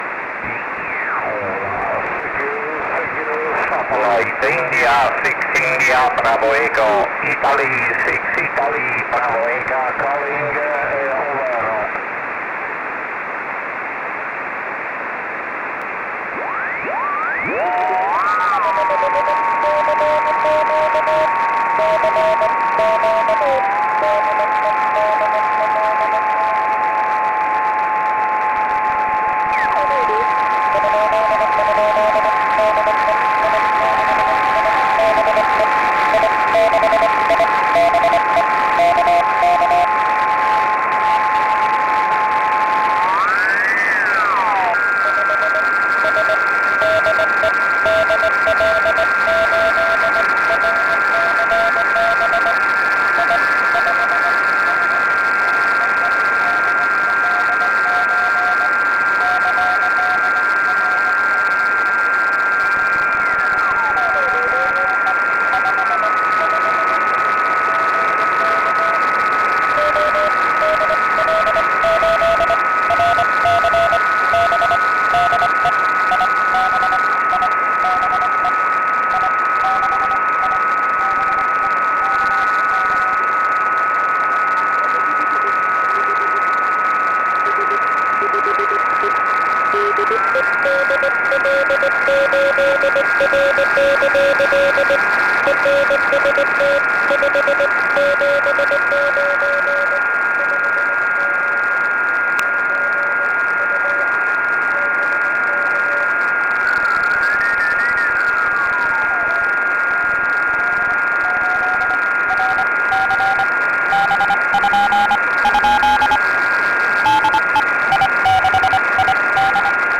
CW